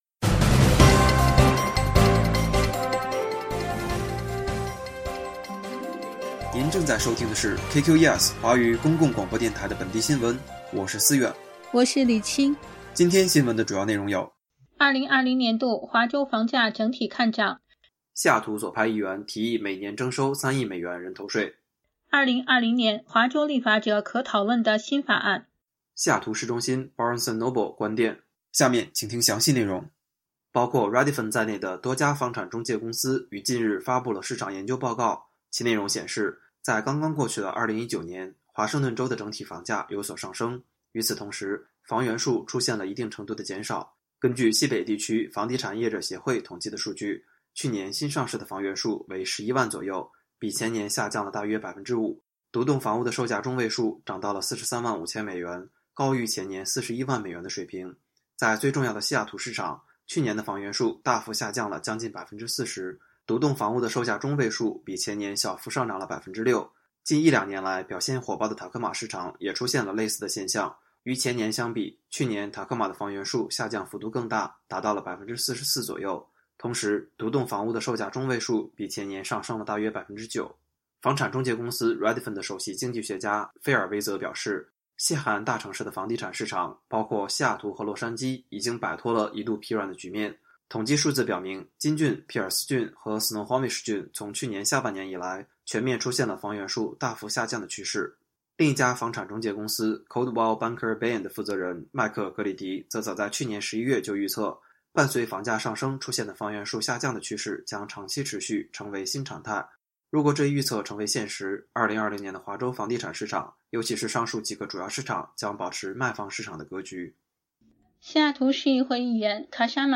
新聞廣播
每日新聞